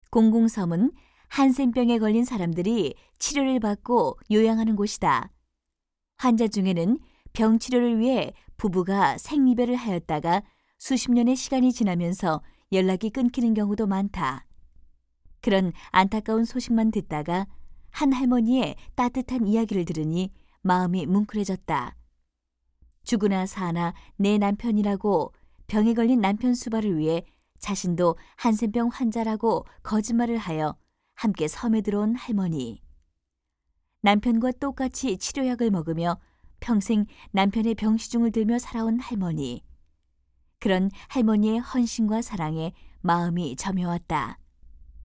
116쪽-내레이션.mp3